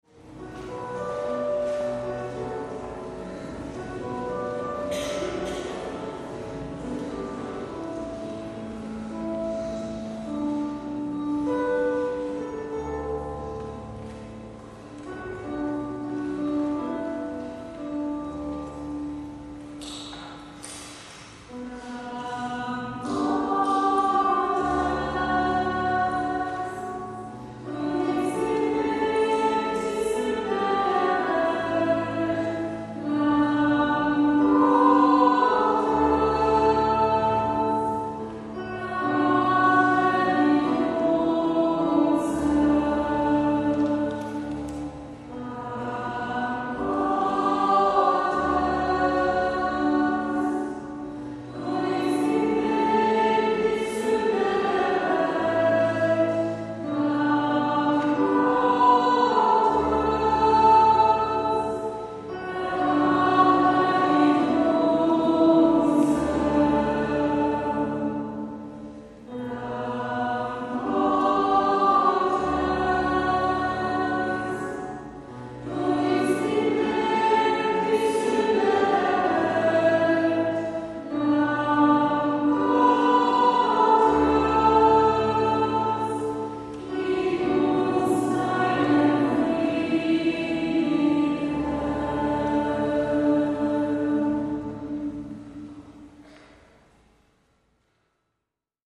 Eucharistiefeier mit Bischof Dr. Alois Schwarz
Agnus Dei: Lamm Gottes, aus "God for you" 2 MB Kantorengruppe